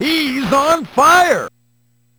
TM88 FireVox.wav